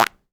Comedy_Cartoon
cartoon_squirt_01.wav